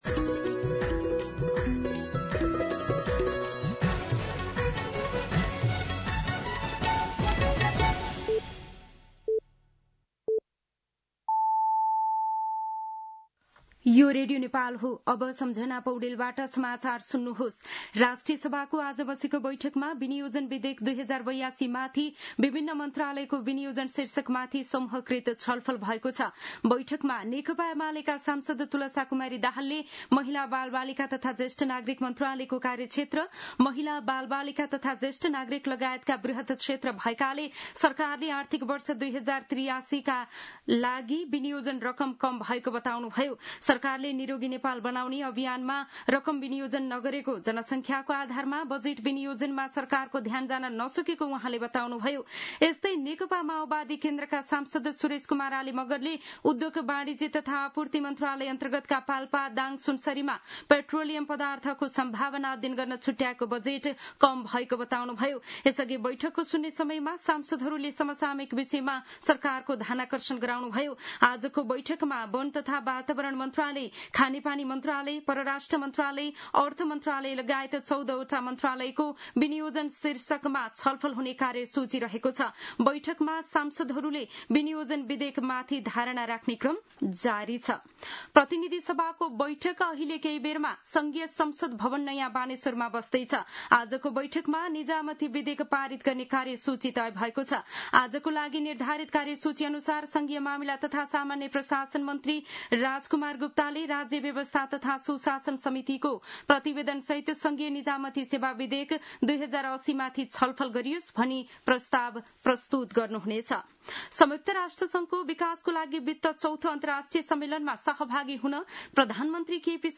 दिउँसो १ बजेको नेपाली समाचार : १५ असार , २०८२